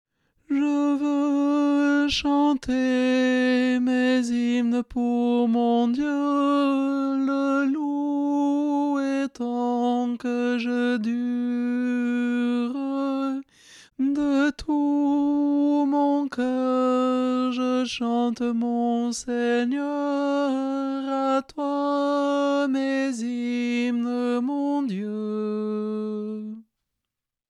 Voix chantée (MP3)COUPLET/REFRAIN
TENOR